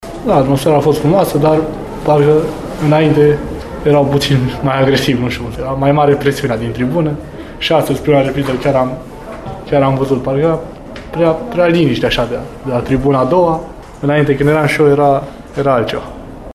După meci, ex-utistul David Miculescu a fost provocat să vorbească despre atmosfera din tribune. Actualul component al campioanei FCSB e de părere că presiunea spectatorilor nu a mai fost la fel de mare ca pe vremea când el îmbrăca tricoul ”Bătrânei Doamne”: